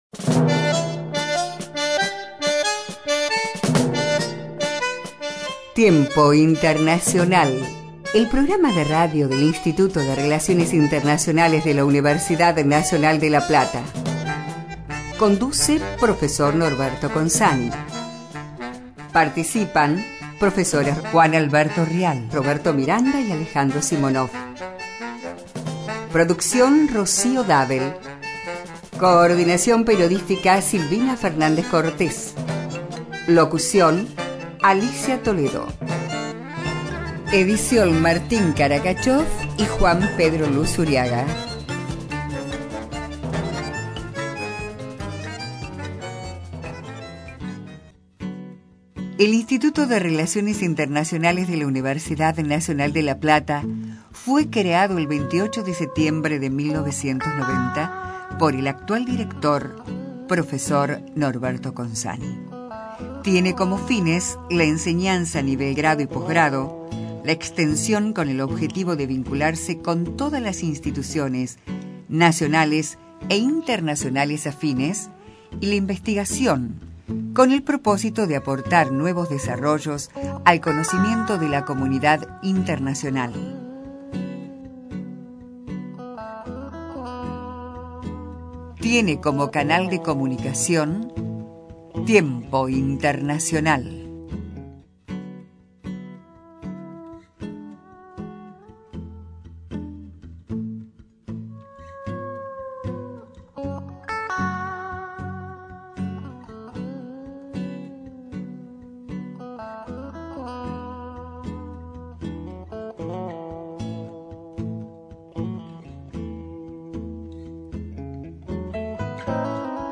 Entrevista al Embajador Joao Baena Soares
Ex secretario General de la OEA